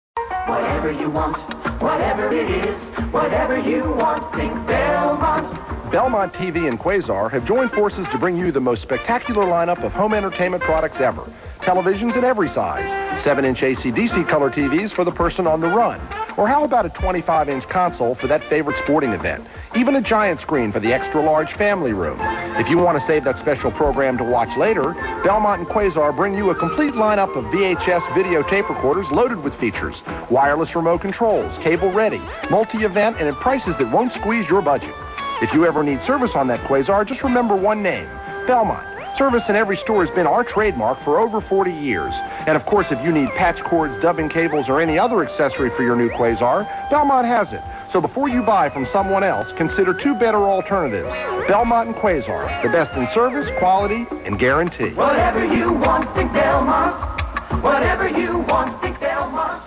empee3 of the commerical [d-lode]